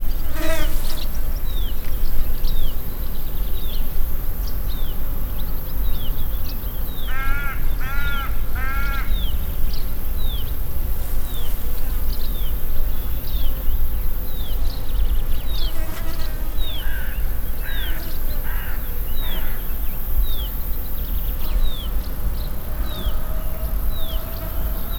egyreten_thassos00.25.WAV